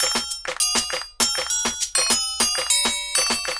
Мой можно сказать первый перкуссионный опыт. По очереди создания - 4-ый луп. 3 инструмента на дорожке.